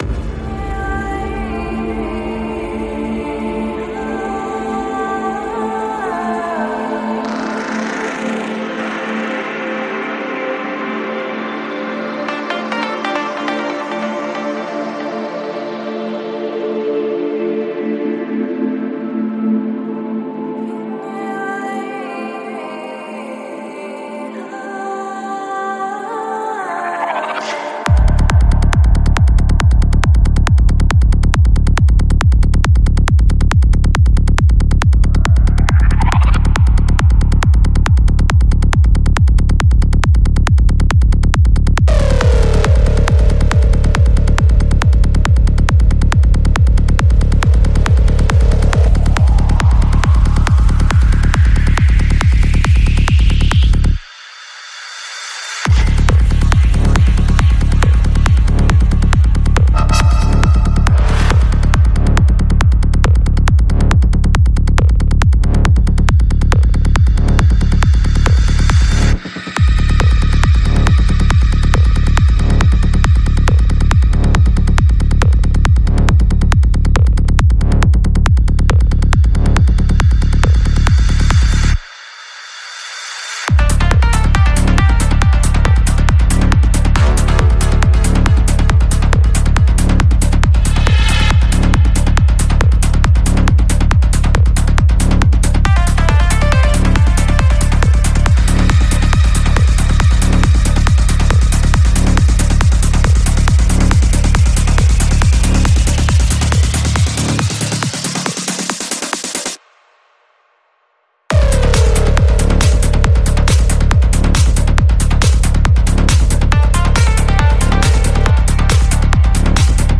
Жанр: Psytrance